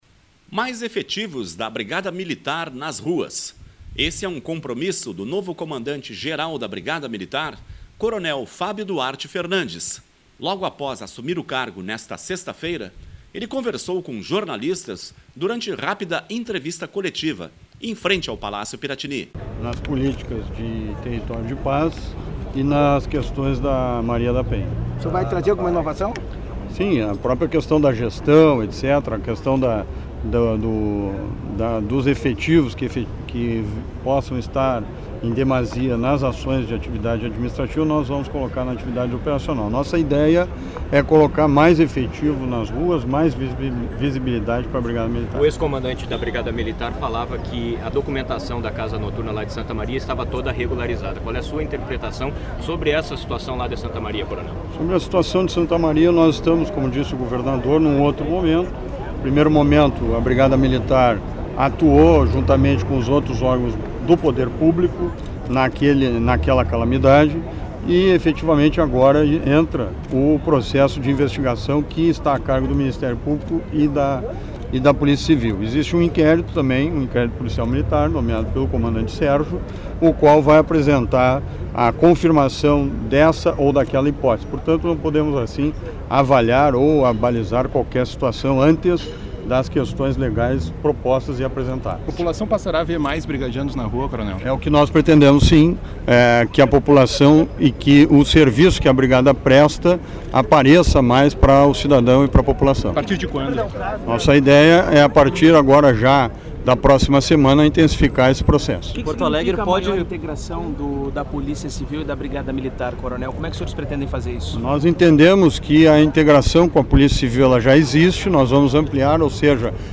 Mais efetivos da Brigada Militar nas ruas: esse é um compromisso do novo comandante-geral da Brigada Militar, coronel Fábio Duarte Fernandes. Logo após assumir o cargo, nesta sexta-feira (1), ele conversou com jornalistas, durante rápida entrevista